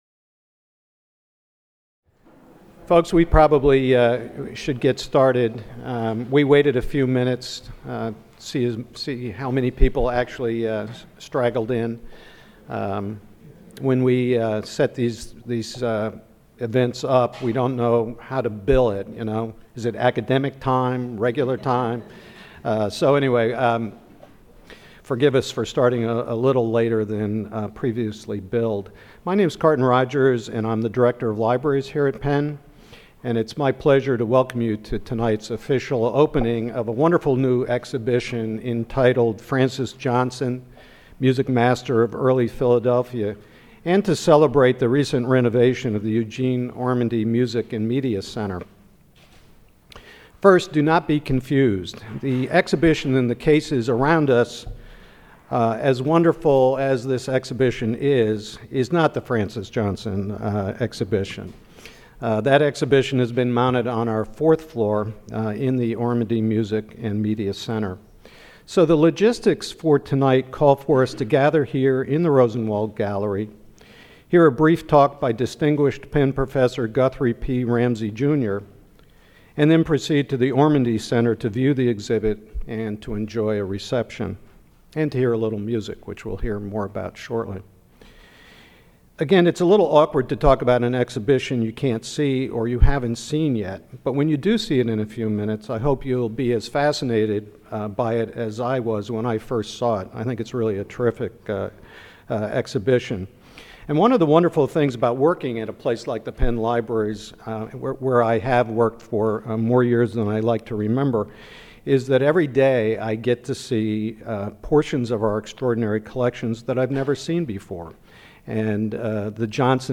His music survives today in piano arrangements published during his lifetime. The lecture marked the opening of an exhibit